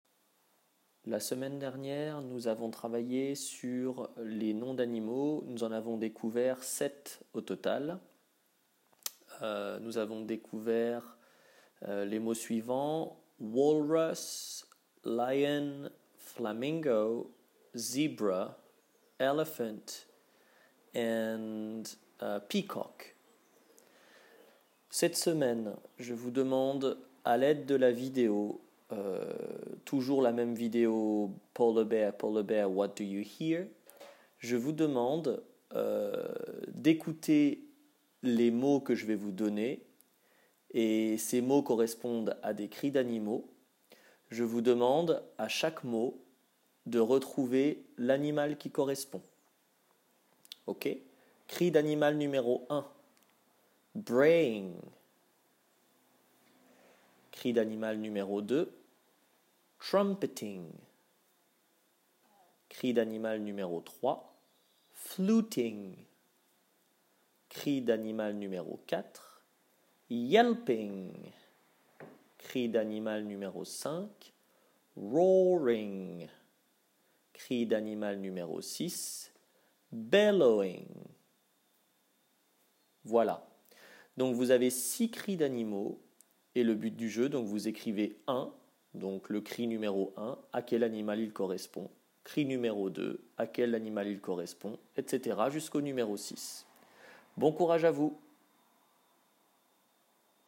1) zebra = braying 2) elephant = trumpeting 3) flamingo = fluting 4) peacock = yelping 5) lion = roaring 6) walrus = bellowing
Cris-d’animaux-anglais.m4a